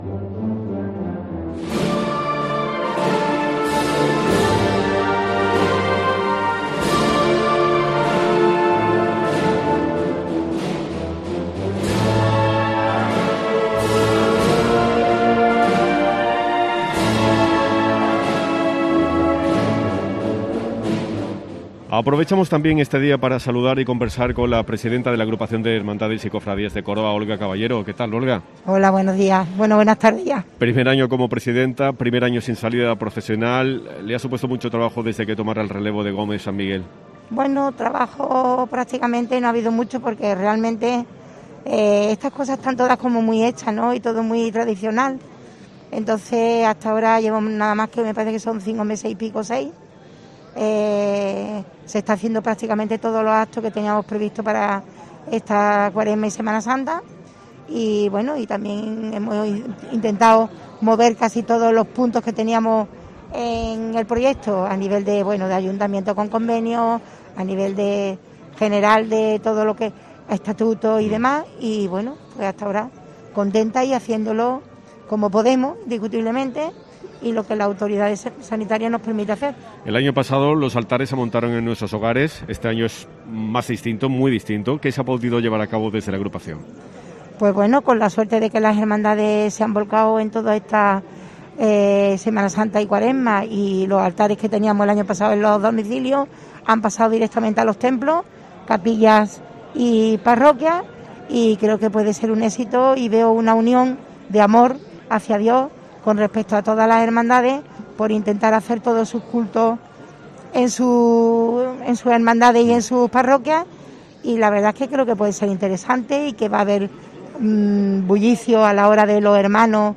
Cada viernes de Dolores, COPE se traslada a la plaza de capuchinos, al epicentro de la fe.